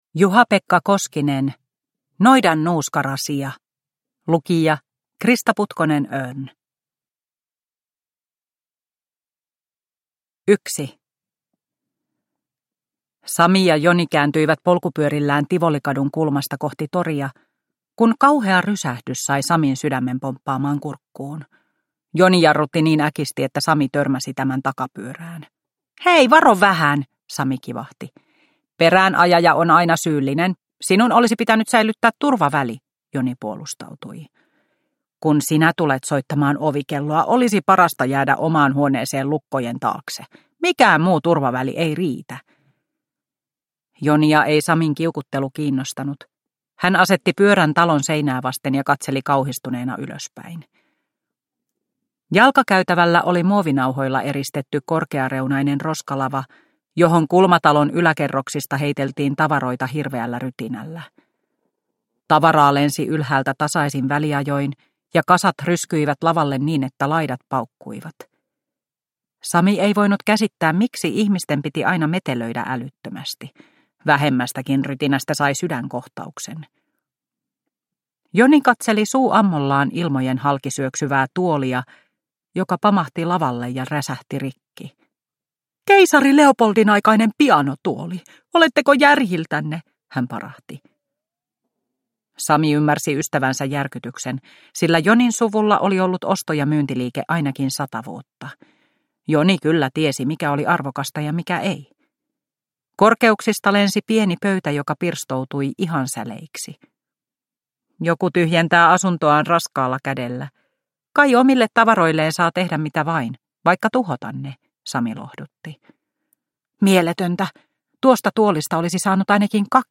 Noidan nuuskarasia – Haavekaupunki 7 – Ljudbok